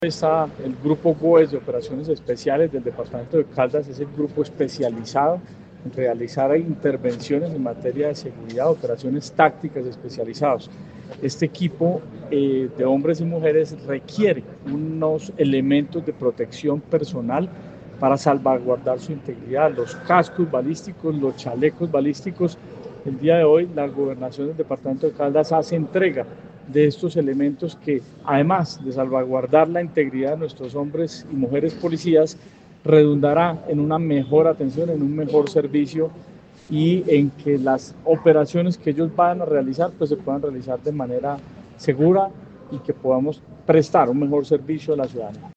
Coronel Alex Durán Santos, comandante del Departamento de Policía Caldas.
Coronel-Alex-Duran-Santos-comandante-del-Departamento-de-Policia-Caldas.mp3